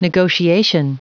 Prononciation du mot negotiation en anglais (fichier audio)
Prononciation du mot : negotiation
negotiation.wav